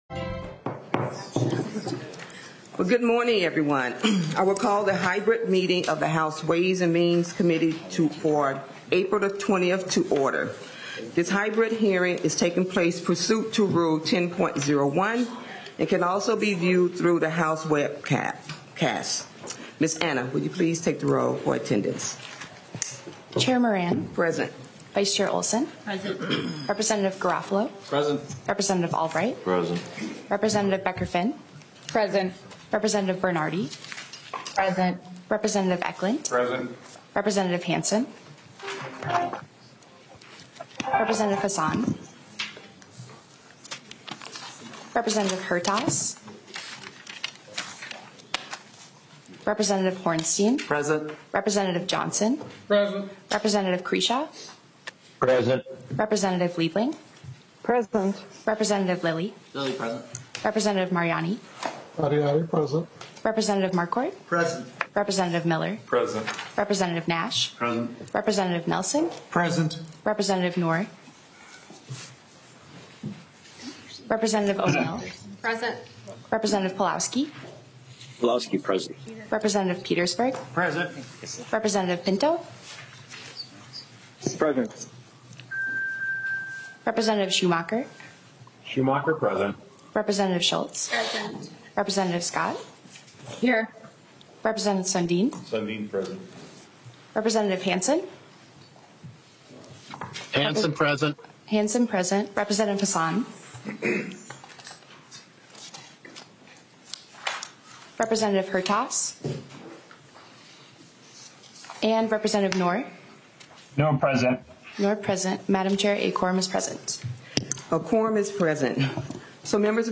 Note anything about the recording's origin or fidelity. This hybrid committee hearing will be held in-person in State Capitol Room 120 with remote participation from members and testifiers available.